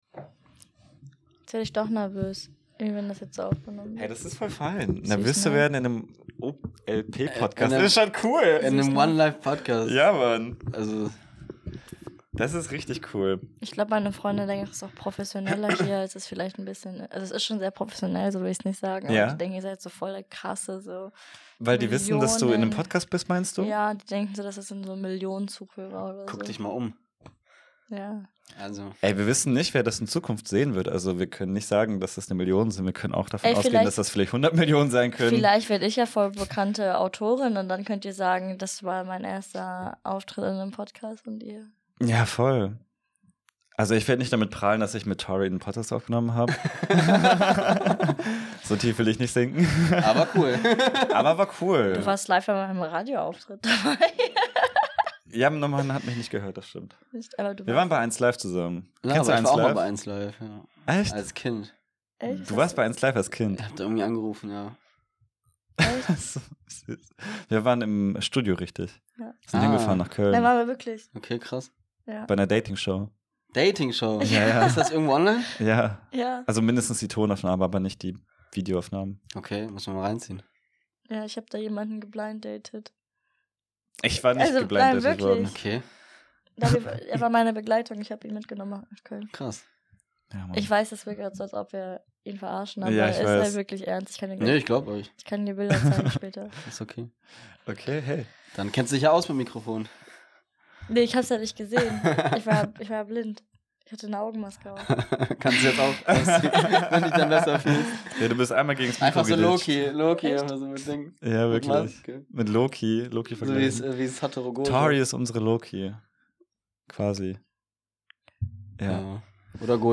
Diese Folge ist weniger Analyse, mehr Vibe.